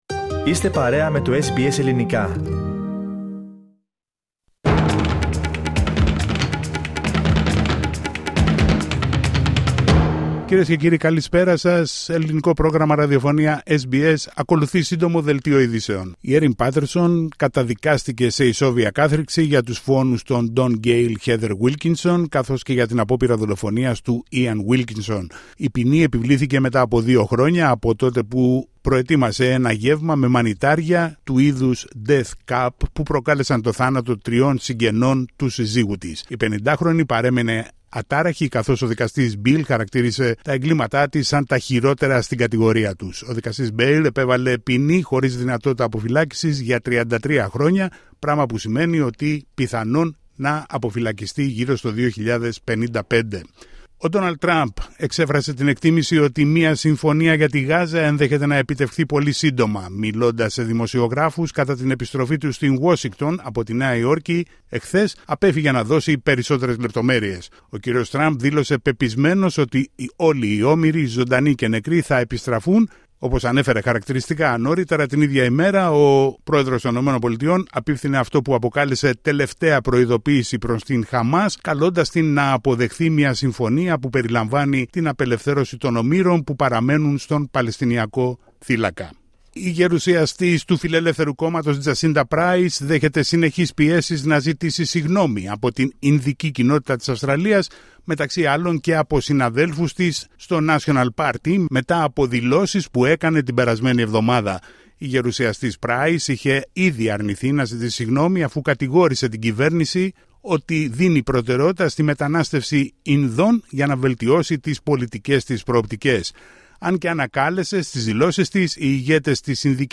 Δελτίο ειδήσεων 8 Σεπτεμβρίου 25 ( News flash)